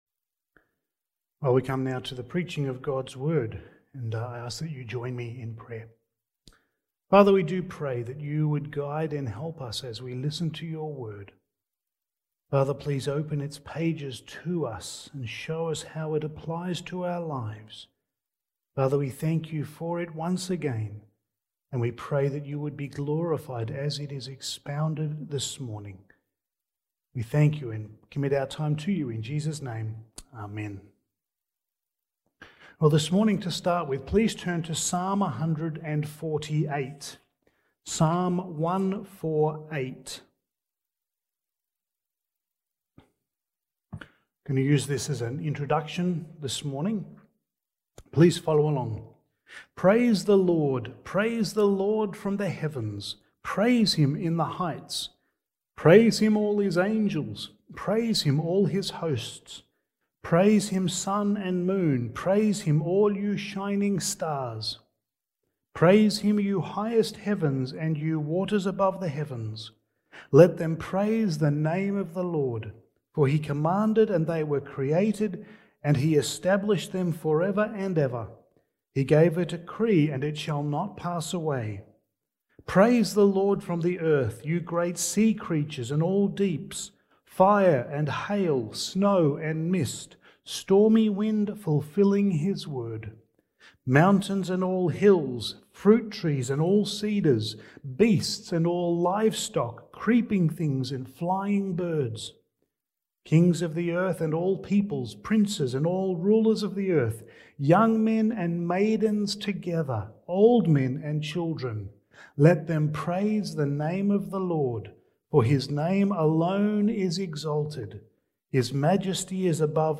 Passage: Acts 11:1-30 Service Type: Sunday Morning